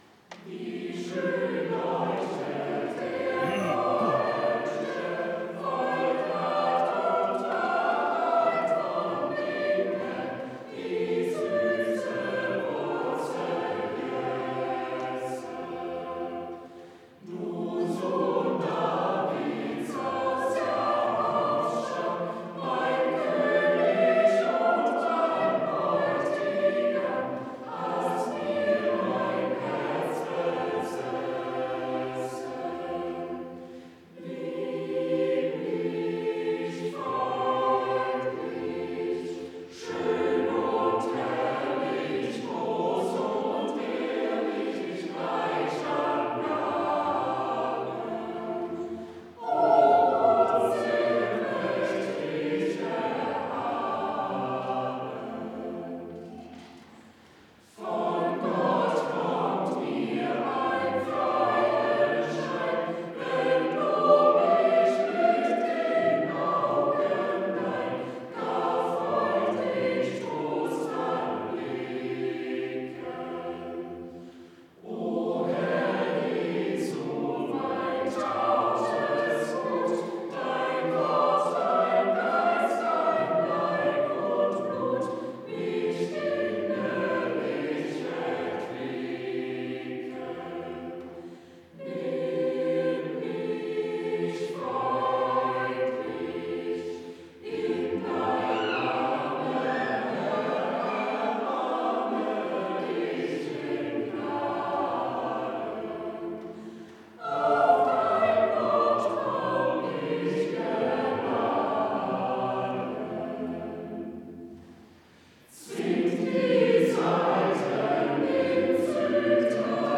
5. Wie schön leuchtet der Morgenstern... Chor der Evangelisch-Lutherische St. Johannesgemeinde Zwickau-Planitz
Audiomitschnitt unseres Gottesdienstes vom Letzten Sonntag nach Epipanias 2026.